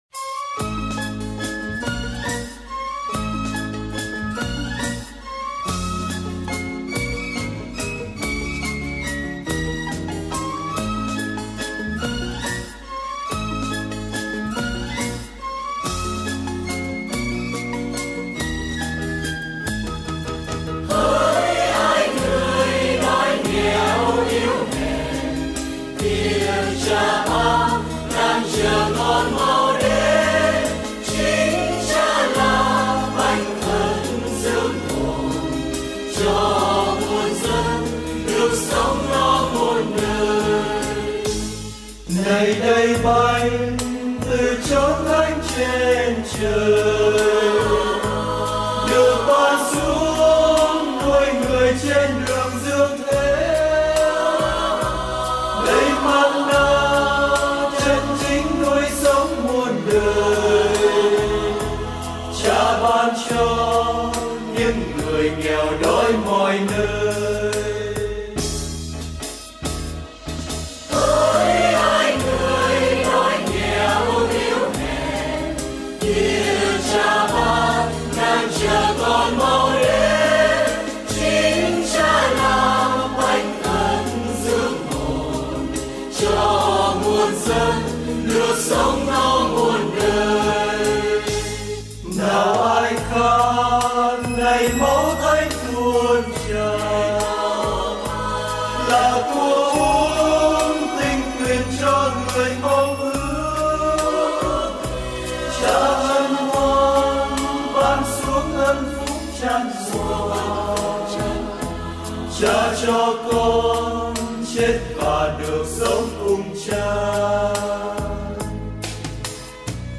Ca Đoàn Hoan Ca St Joseph Springvale Melbourne
Thánh Ca DỰ TIỆC THÁNH - Nhạc và Lời : Vũ Kim
DuTiecThanh_VuKim_CaDoanHoanCa_StJoseph_Springdale_Melbourne.mp3